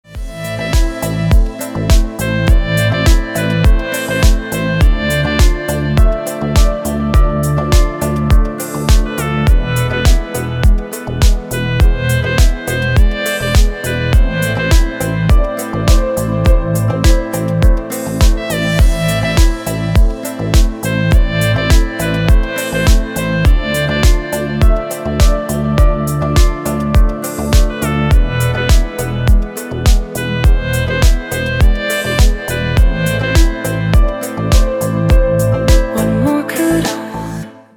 Спокойные рингтоны